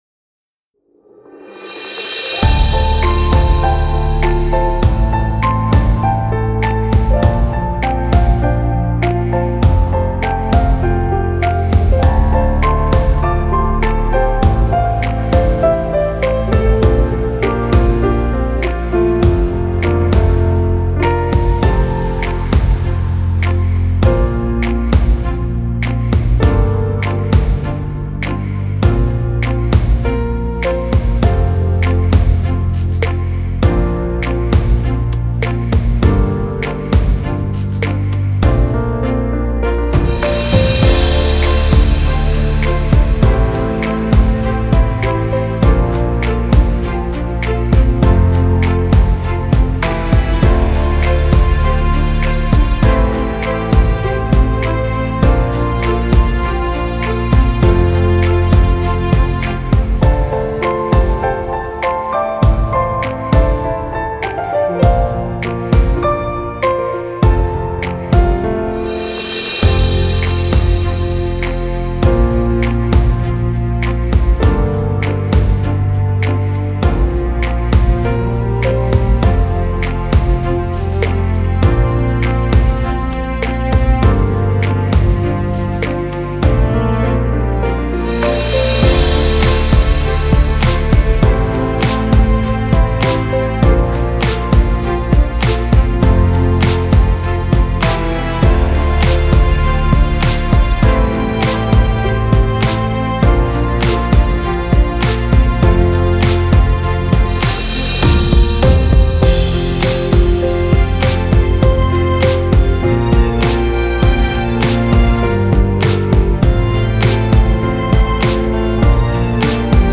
„Mano namai“ fonograma